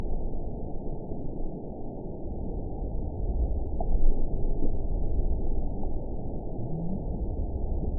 event 917768 date 04/15/23 time 17:16:20 GMT (2 years ago) score 8.46 location TSS-AB05 detected by nrw target species NRW annotations +NRW Spectrogram: Frequency (kHz) vs. Time (s) audio not available .wav